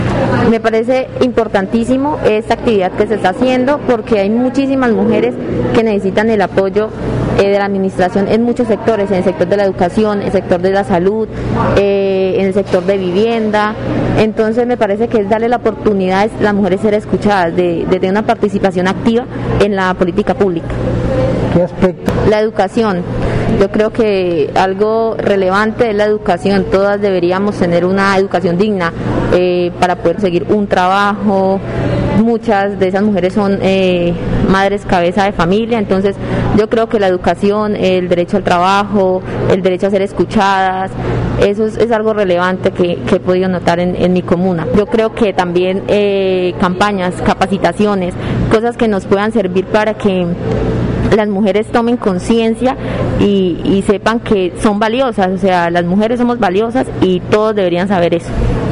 Descargar audio: Andrea Pabón, edil de la Comuna 17